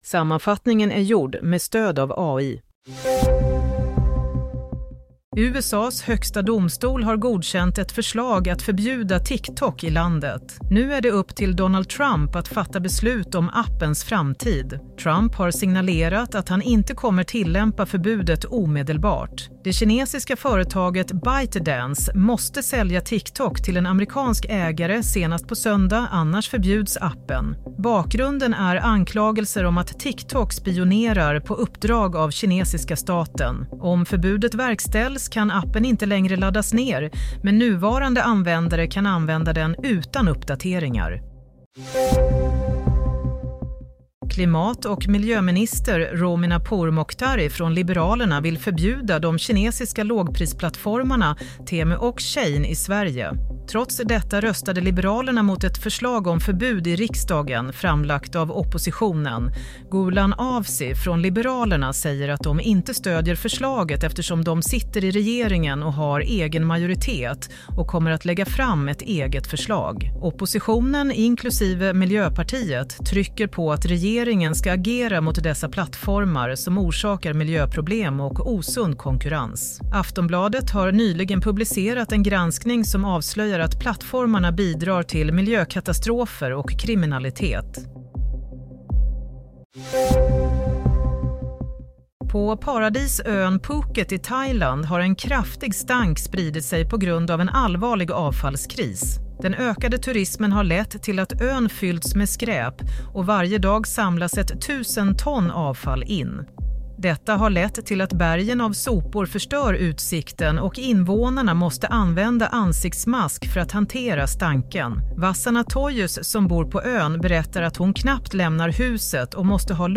Nyhetssammanfattning – 17 januari 22:00
Sammanfattningen av följande nyheter är gjord med stöd av AI.